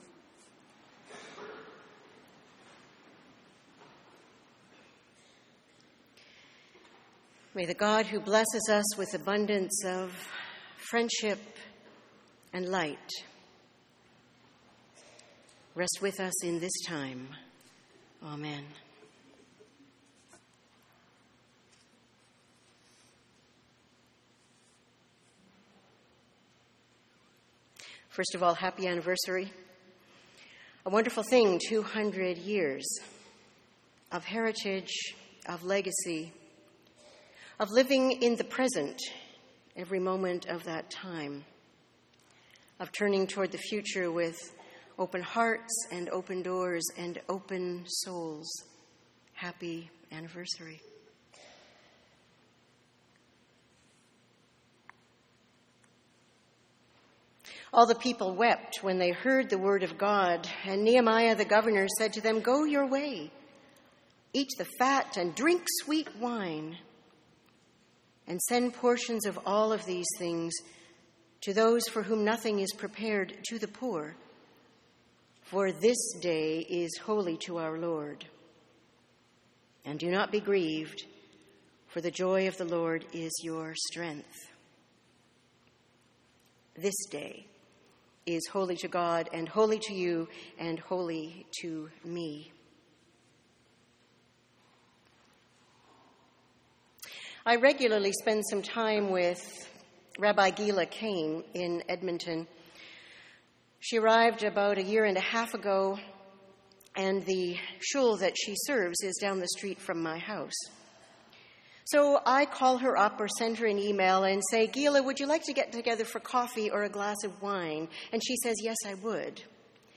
Sermons & Livestreams | Metropolitan United Church